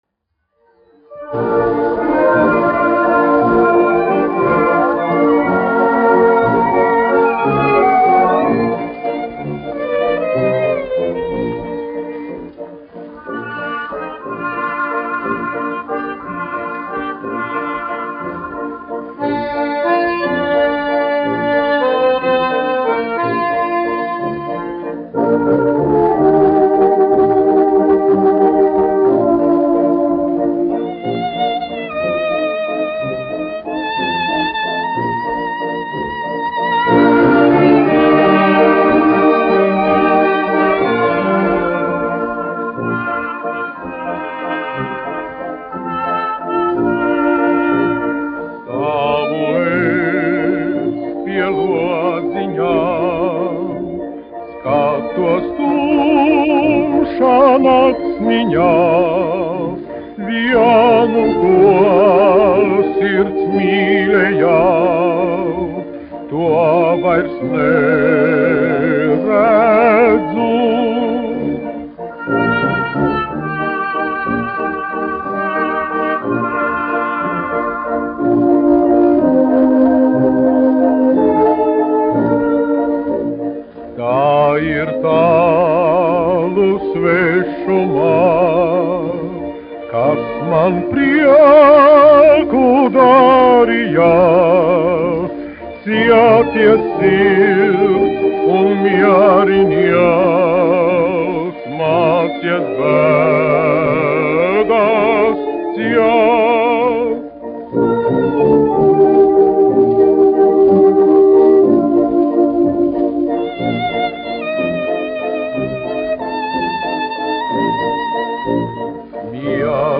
1 skpl. : analogs, 78 apgr/min, mono ; 25 cm
Populārā mūzika
Valši
Skaņuplate
Latvijas vēsturiskie šellaka skaņuplašu ieraksti (Kolekcija)